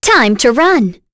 Selene-Vox_Skill3.wav